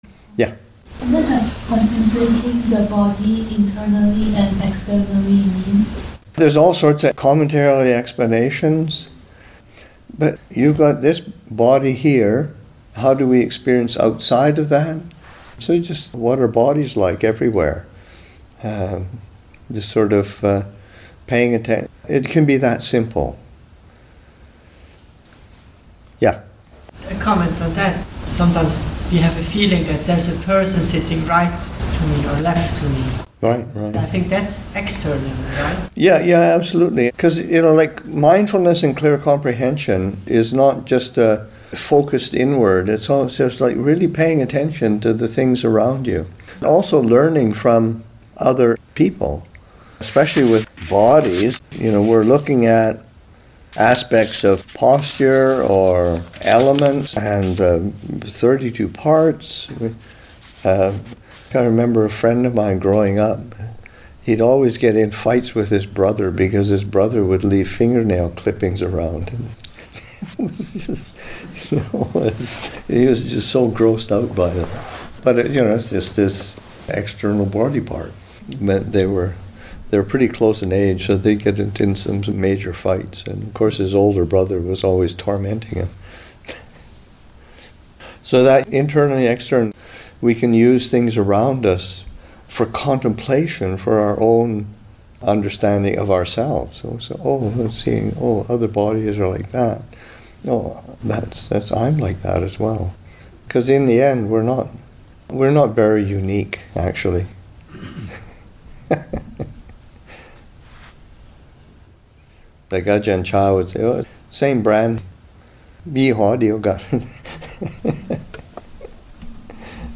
Readings from The Island [2025], Session 29, Excerpt 3